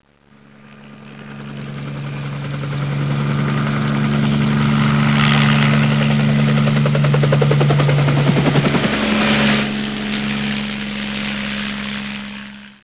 heli02left2right.wav